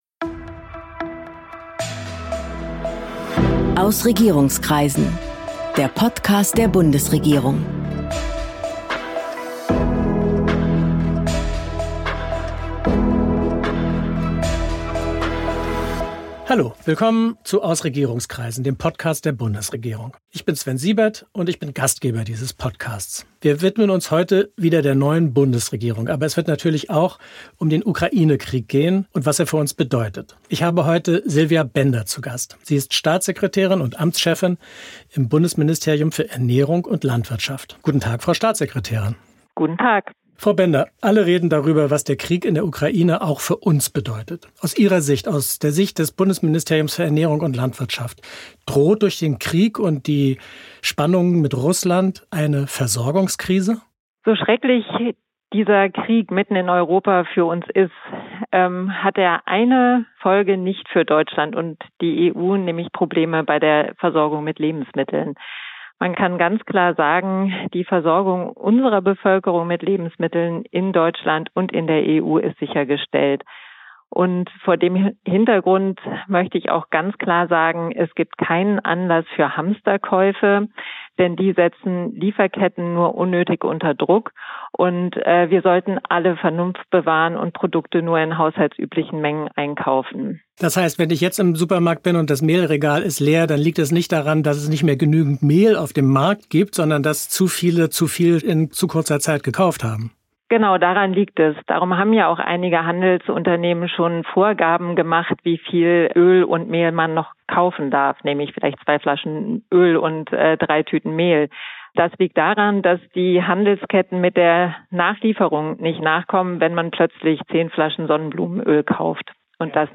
Sorgt der Krieg in der Ukraine für eine Lebensmittelkrise in Deutschland? Silvia Bender, Staatssekretärin im Bundeslandwirtschaftsministerium (BMEL), schätzt die Lage im Podcast ein. Außerdem erklärt sie, warum unser Land weniger Fleisch produzieren muss, was das BMEL für den Klimaschutz tut und wie ein fairerer Umgang mit landwirtschaftlichen Betrieben gelingt.